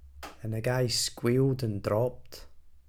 glaswegian
scottish